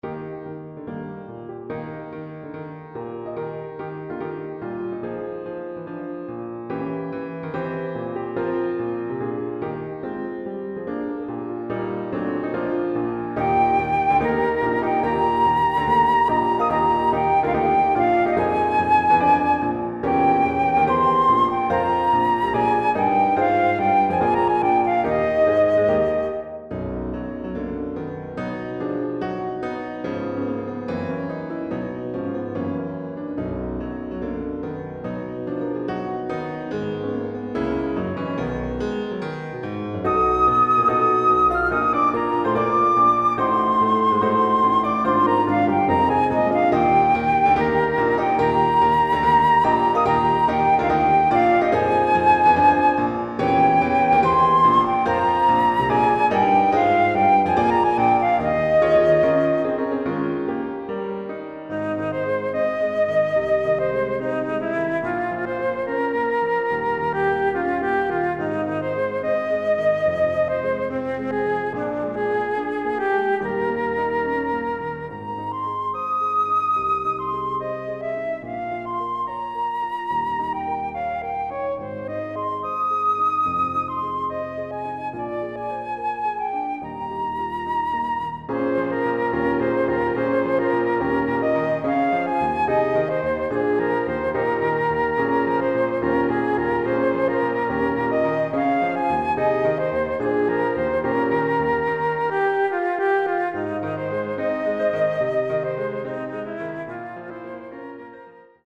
für Flöte und Klavier